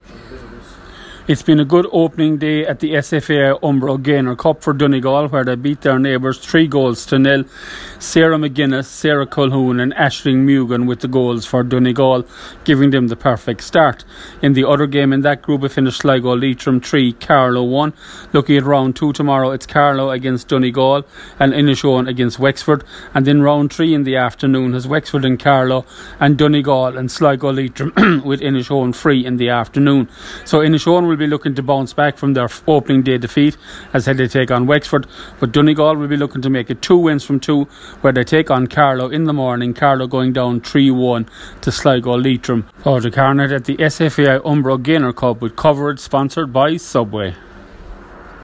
reports: